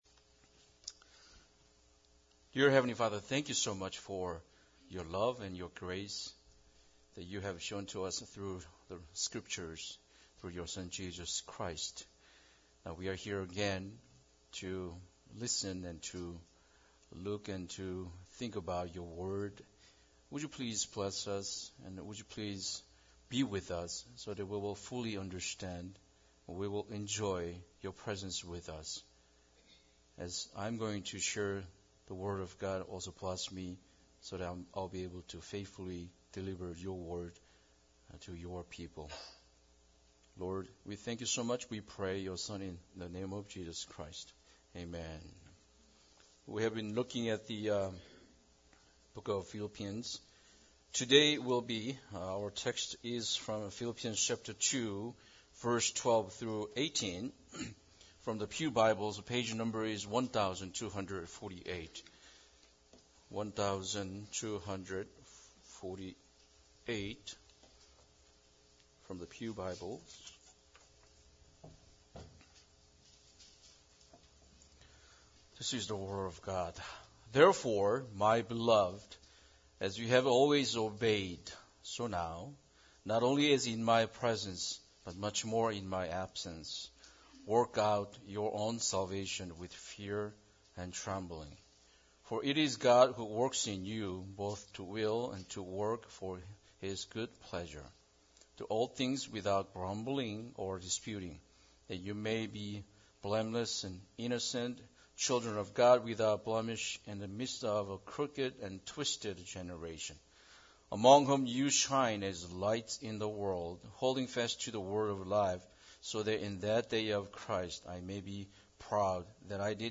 Philippians 2:12-18 Service Type: Sunday Service Bible Text